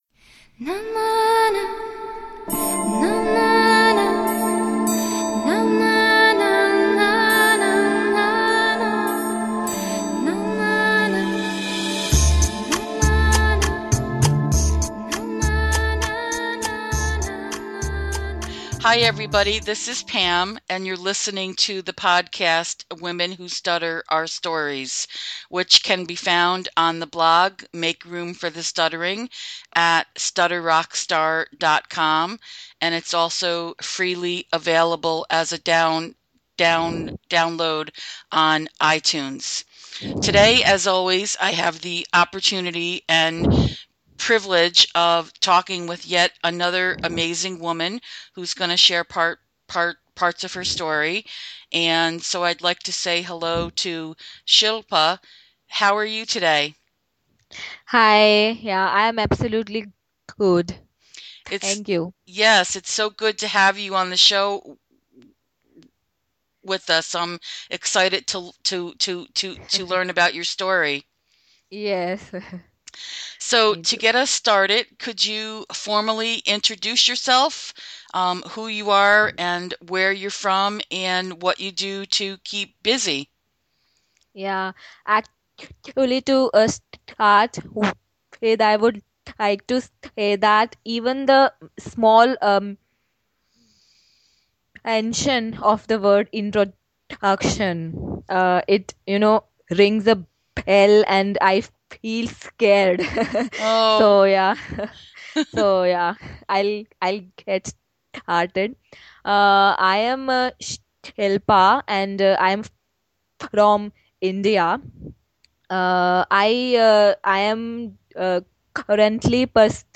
This was an enlightening, honest conversation that could have gone on for hours.